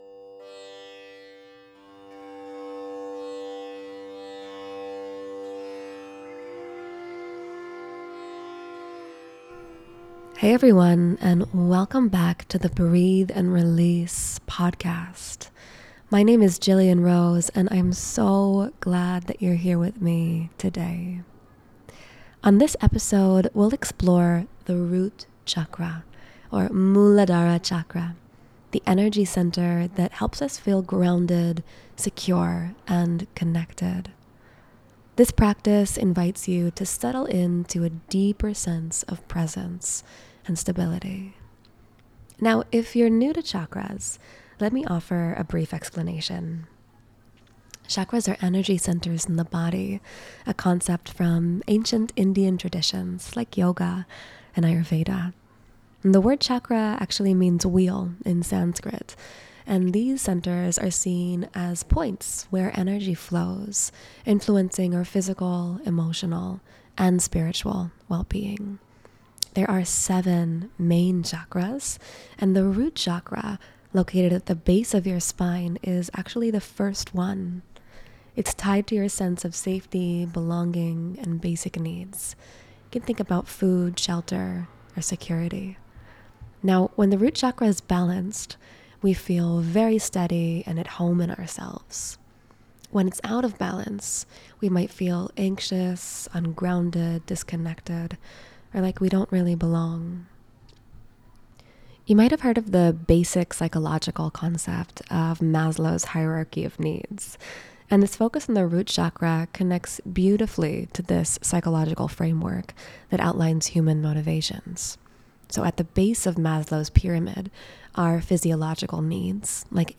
This 15 minute guided meditation explores the root chakra, or Muladhara, the energy center that helps us feel grounded, secure, and connected.